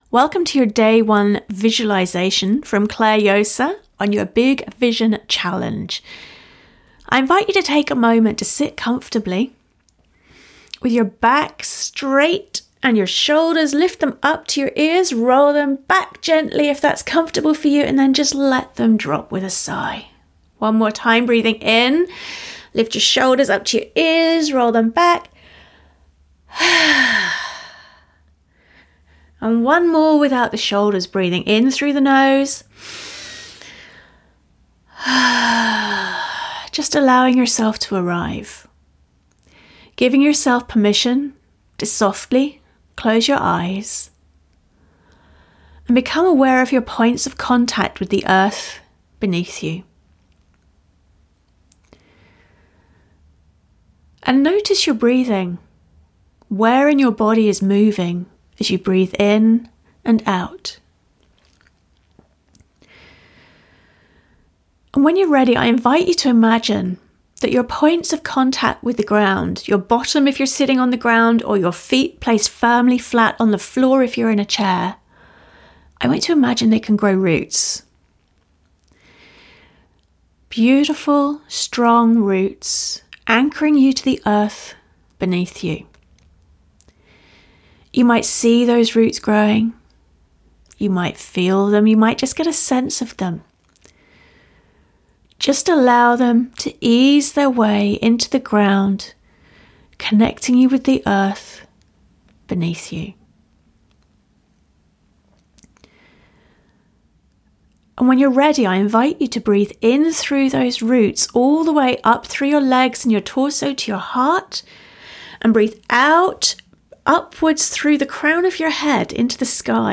Your MP3 Guided Visualisation: